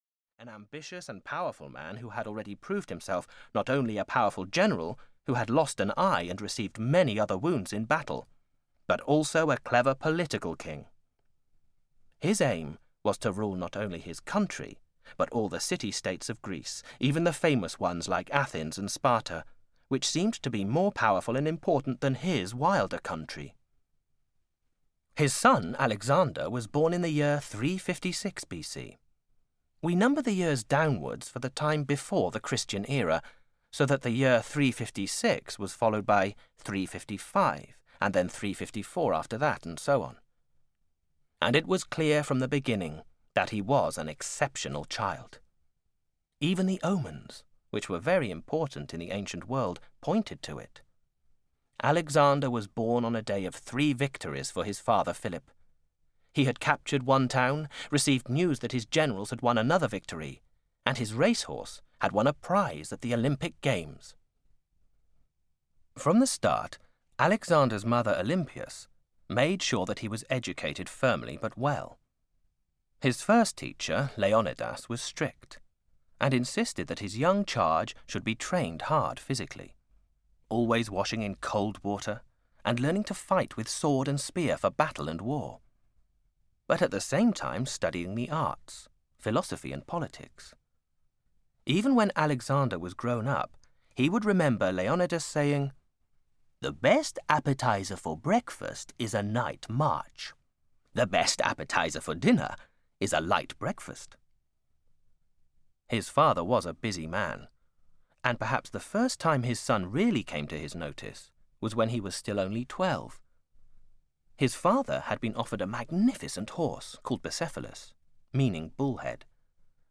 Famous People in History – Volume 2 (EN) audiokniha
Ukázka z knihy